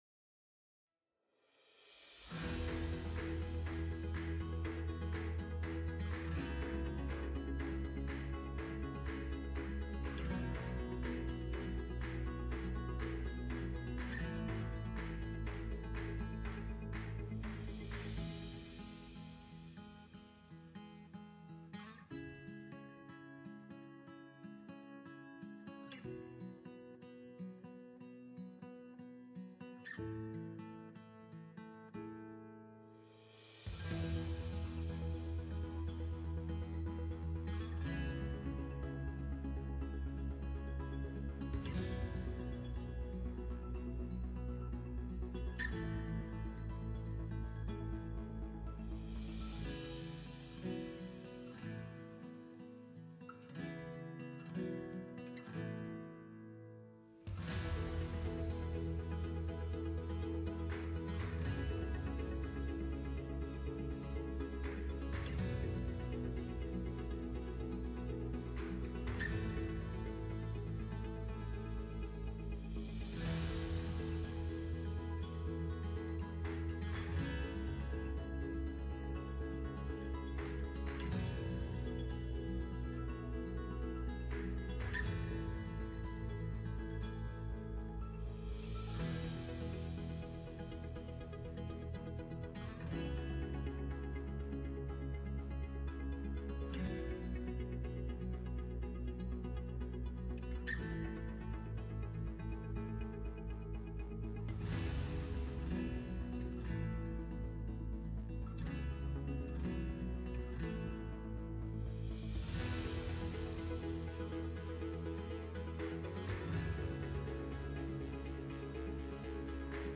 Another Sunday of amazing worship from our super talented team.